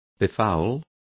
Complete with pronunciation of the translation of befouls.